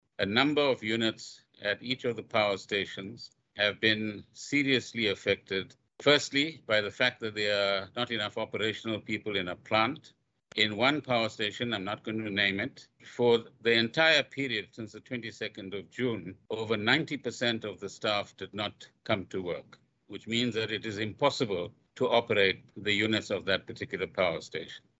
Gordhan yesterday, during a press briefing, announced that a wage agreement was reached yesterday between unions and Eskom.